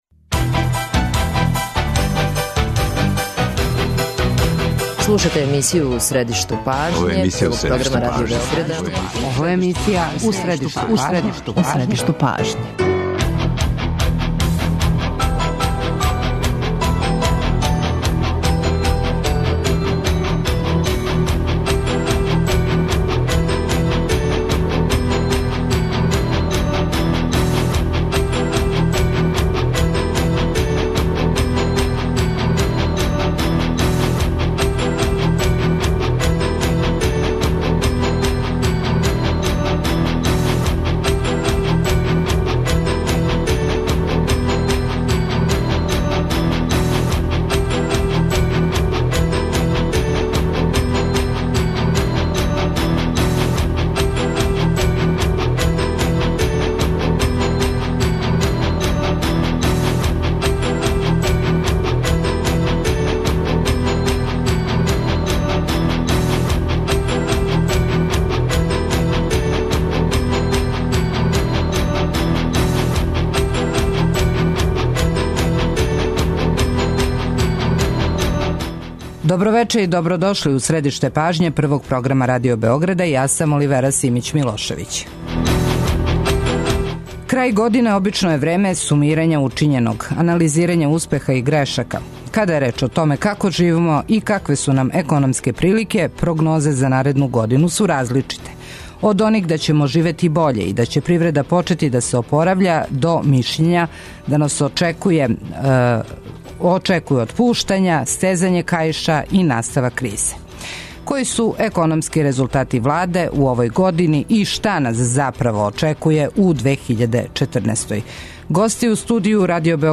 Гости емисије су економисти - Дејан Шошкић, професор Економског факултета и бивши гувернер Народне банке Србије и Душан Вујовић, бивши експерт Светске банке, професор на Факултету за економију, финансије и администрацију.
преузми : 25.86 MB У средишту пажње Autor: Редакција магазинског програма Свакога радног дана емисија "У средишту пажње" доноси интервју са нашим најбољим аналитичарима и коментаторима, политичарима и експертима, друштвеним иноваторима и другим познатим личностима, или личностима које ће убрзо постати познате.